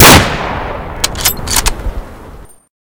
kar98k_shoot.ogg